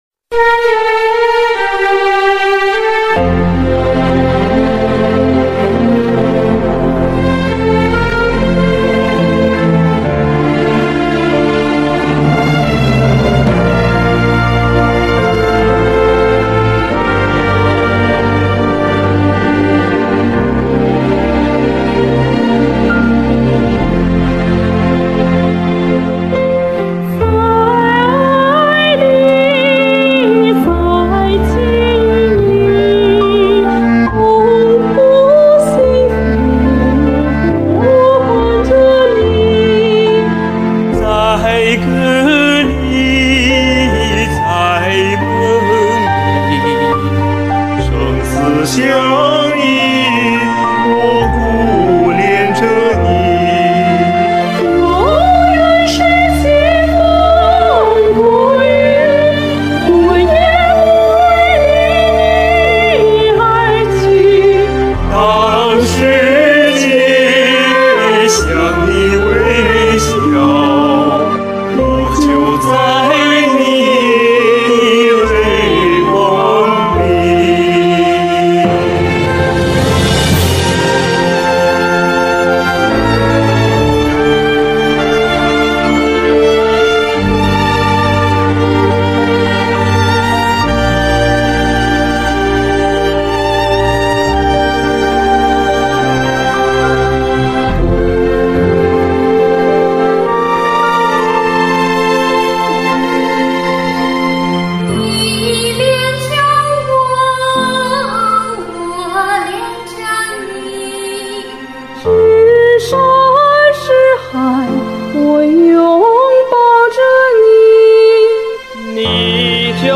大家的歌声感情真挚、优美动人，充盈着华夏儿女对祖国母亲、美好生活的热爱，引发了大家强烈的共鸣。